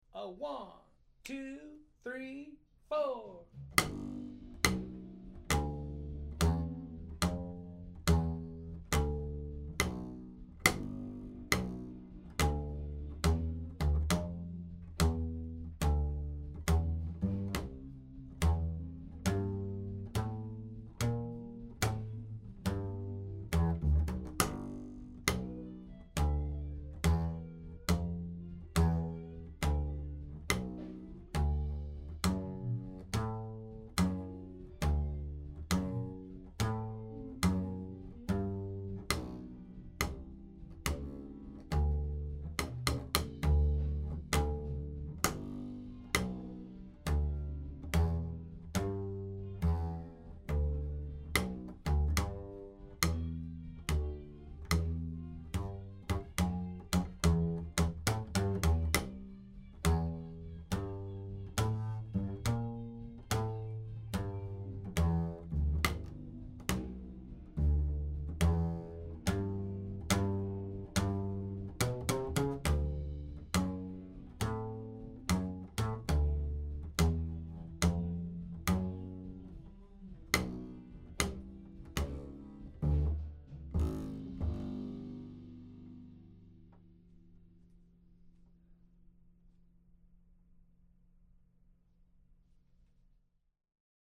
a slow blues in G